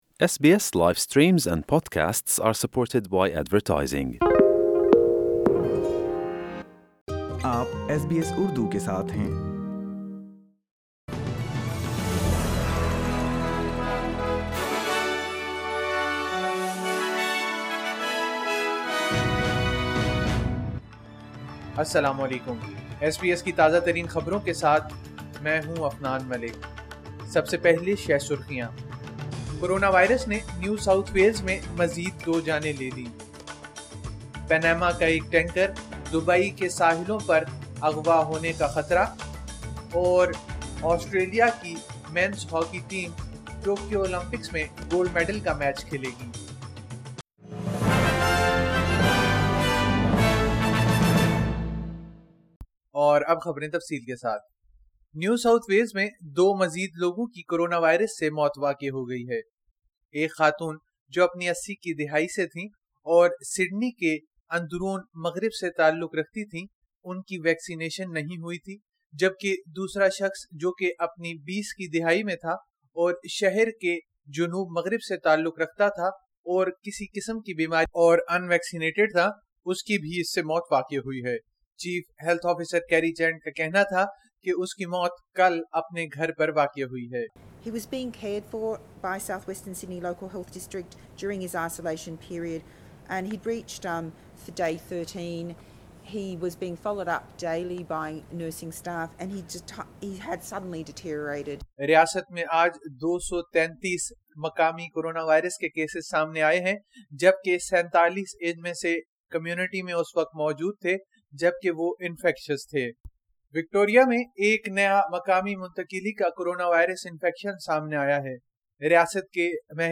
SBS Urdu News 04 August 2021